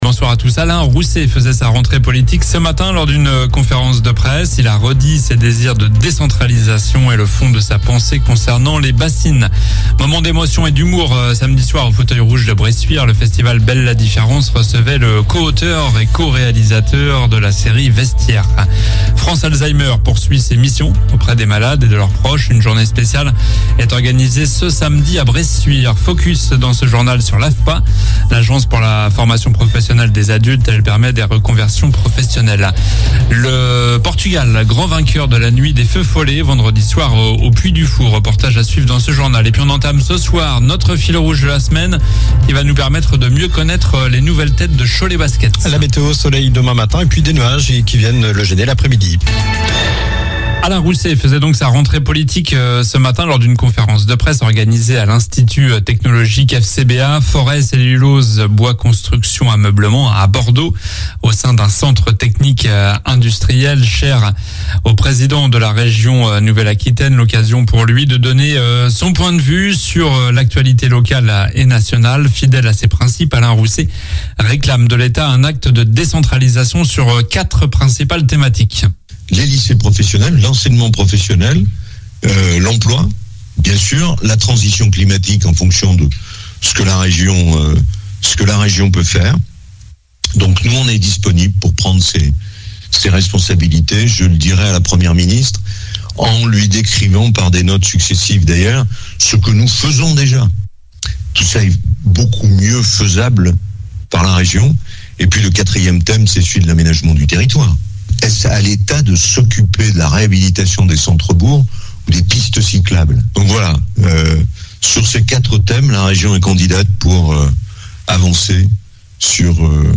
Journal du lundi 19 septembre (soir)